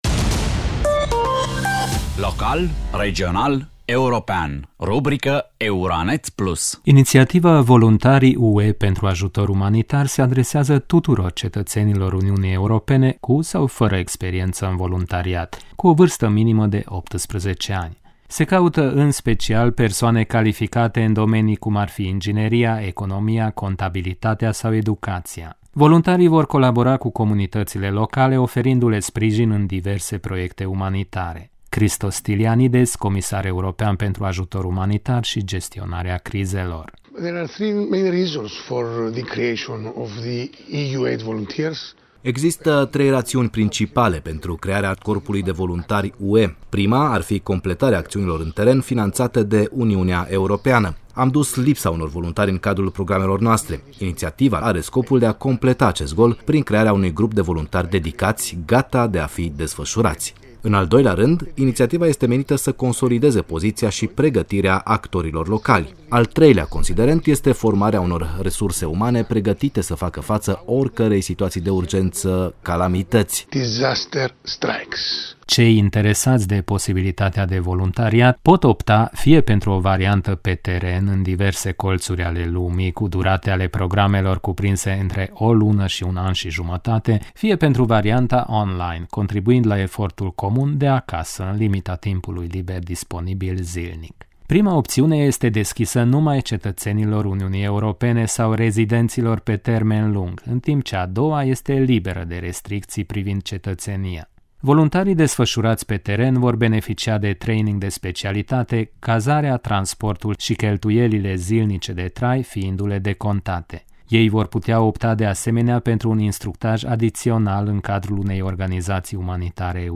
Christos Stylianides, comisar european pentru ajutor umanitar și gestionarea crizelor: „Există trei raţiuni principale pentru crearea corpului de voluntari UE.